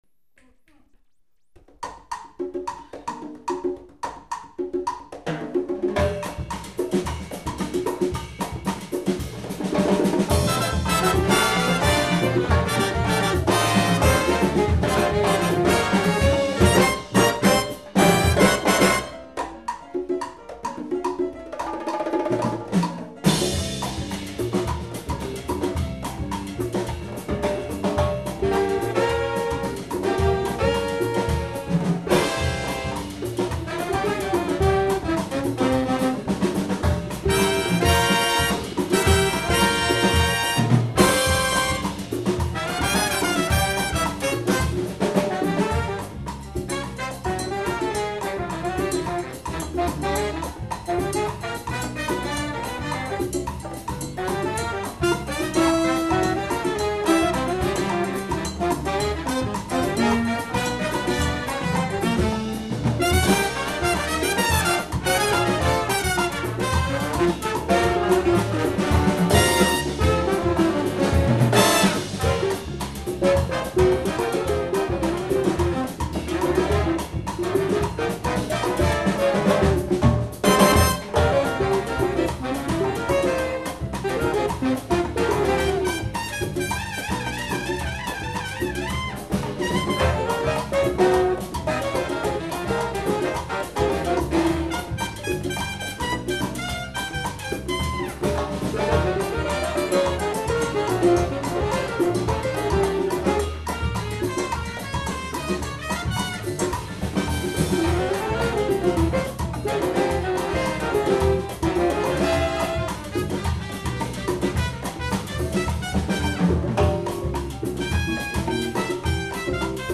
Category: little big band (3-3-3)
Style: mambo
Solos: trumpet 2